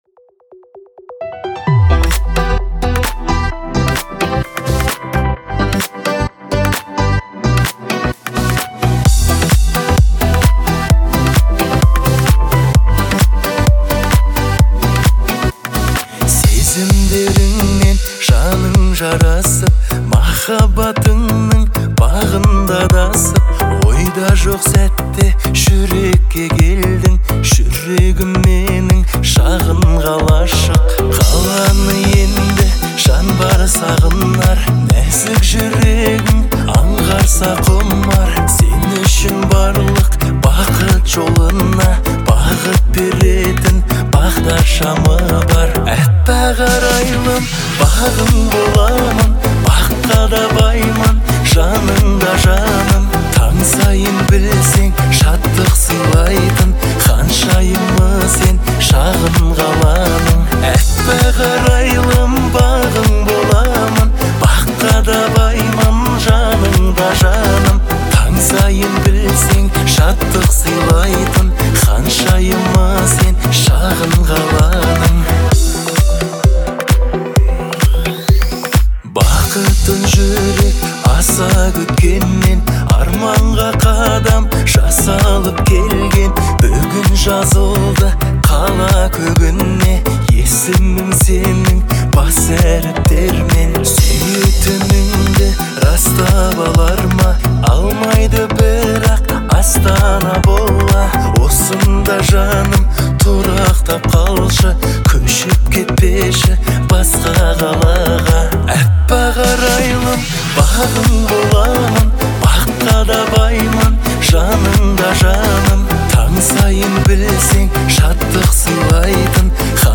Звучание песни отличается мелодичностью и душевностью